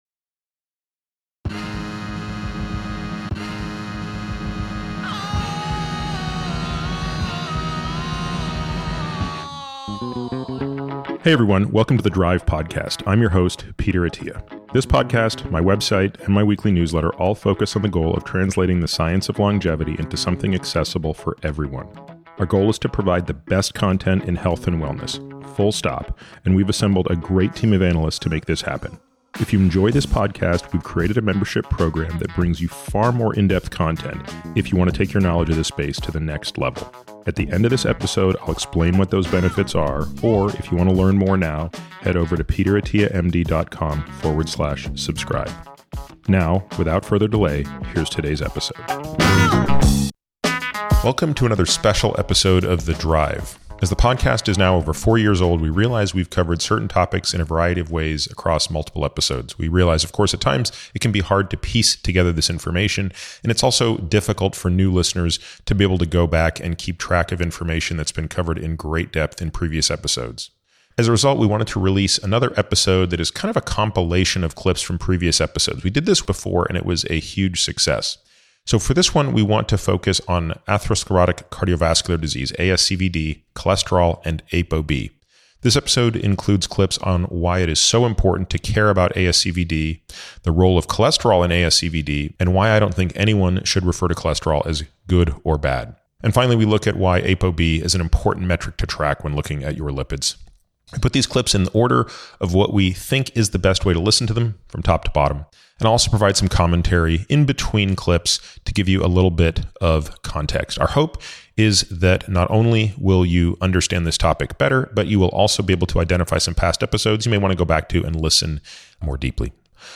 In this special episode of The Drive, we have pulled together a variety of clips from previous podcasts about cardiovascular disease to help listeners understand this topic more deeply, as well as to identify previous episodes which may be of interest. In this episode, Peter highlights the importance of understanding cardiovascular disease and why early intervention is critical. He also provides a primer on lipoproteins and explains the fallacy of the terms “good cholesterol” and “bad cholesterol.”